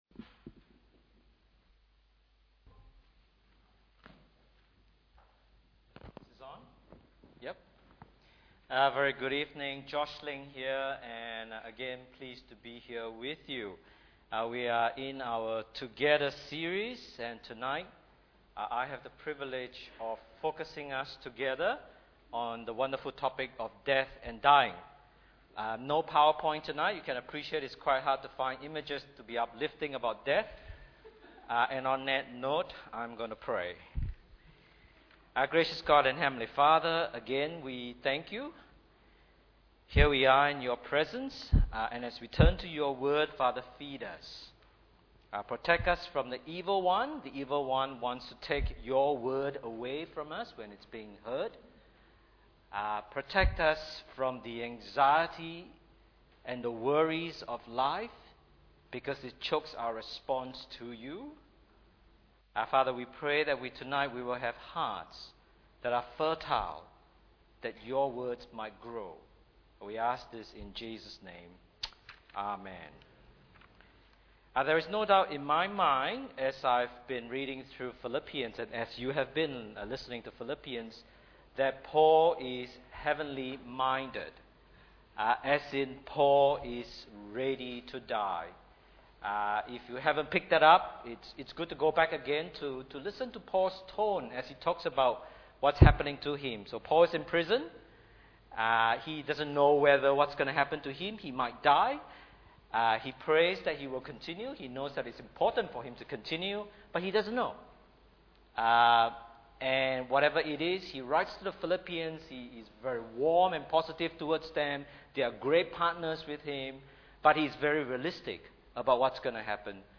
Bible Text: Philippians 4:1-9 | Preacher